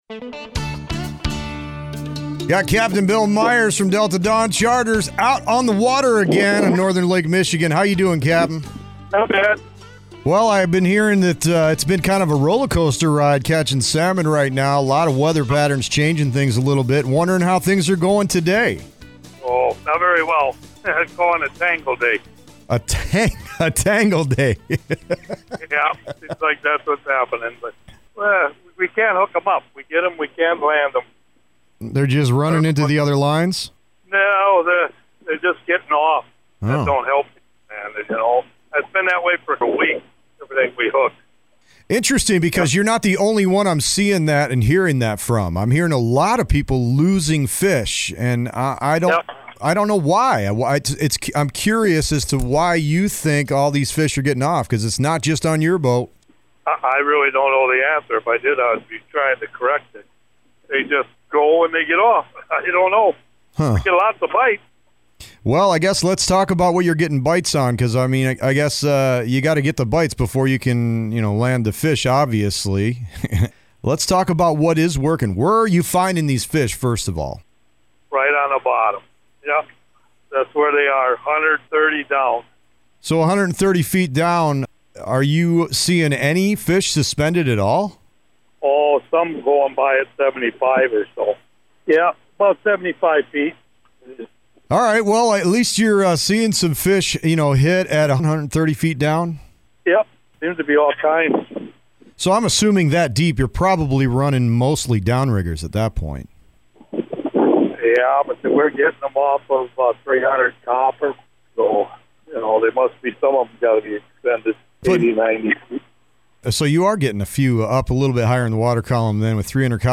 joined the show while on the waters of northern Lake Michigan to talk about salmon fishing in the U.P.